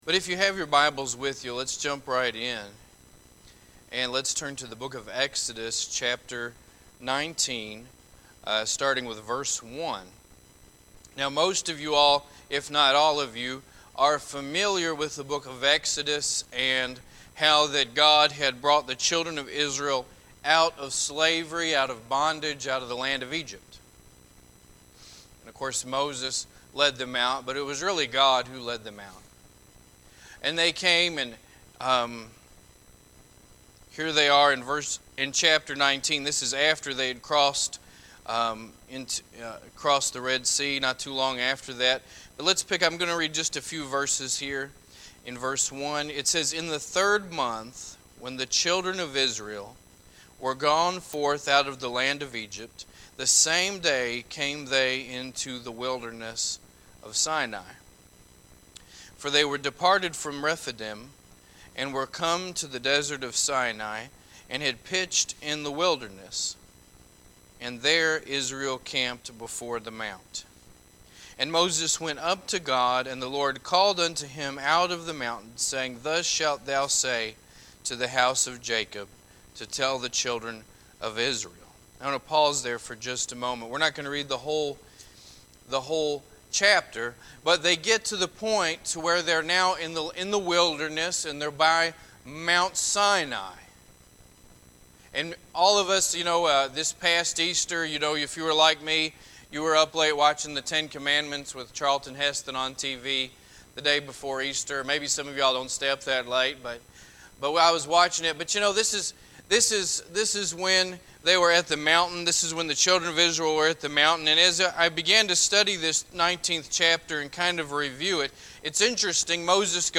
Sunday evening service from 2017-04-20 at Old Union Missionary Baptist Church in Bowling Green, Kentucky.